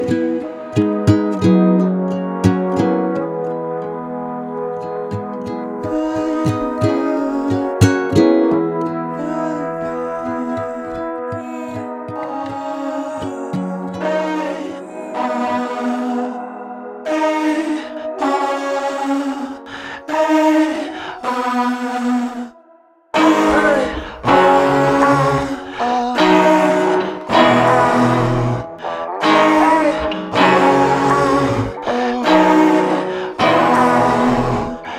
Жанр: Поп / Русские
# Pop in Spanish